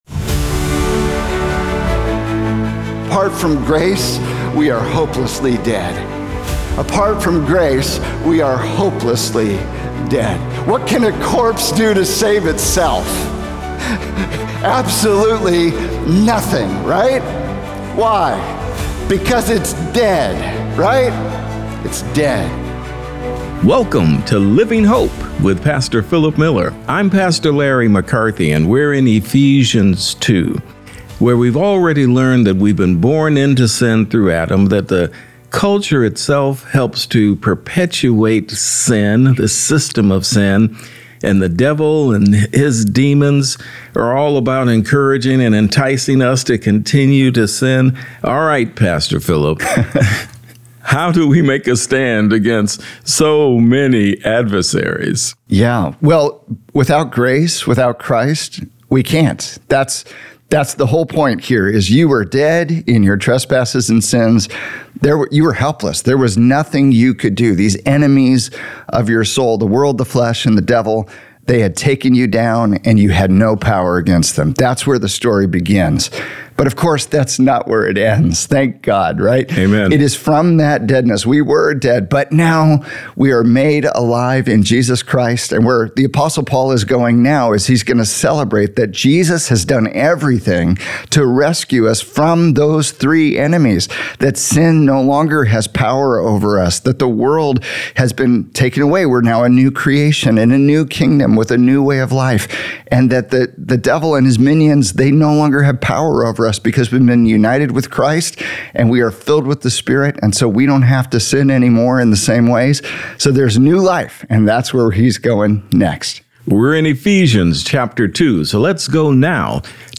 Jesus’ Offer of Resurrection Life | Radio Programs | Living Hope | Moody Church Media